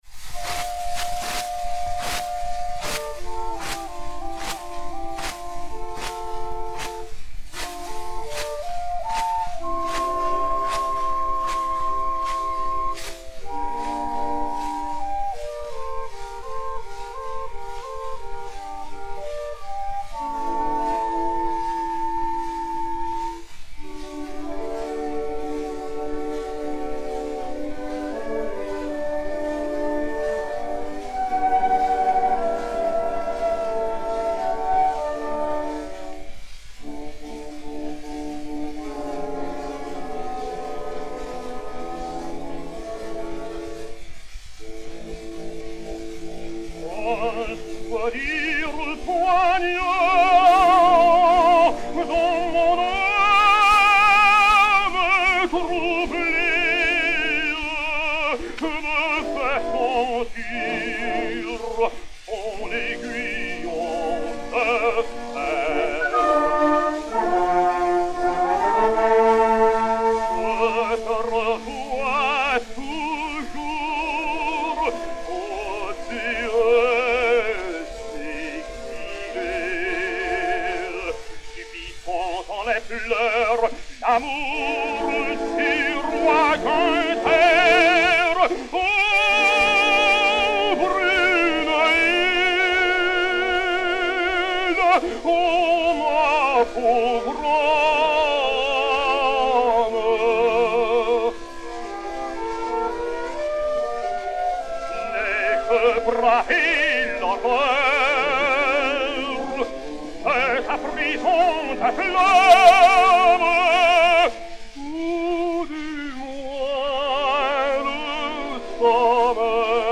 Léon Campagnola (Sigurd) et Orchestre
Disque Pour Gramophone 4-32295, mat. 16962u, réédité sur U 11, enr. à Paris le 27 novembre 1911